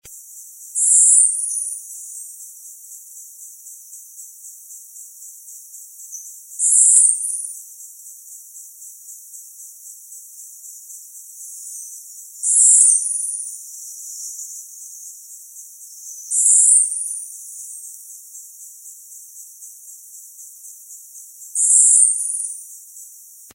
Afrechero Plomizo (Haplospiza unicolor)
Nombre en inglés: Uniform Finch
Provincia / Departamento: Misiones
Localidad o área protegida: Bio Reserva Karadya
Condición: Silvestre
Certeza: Vocalización Grabada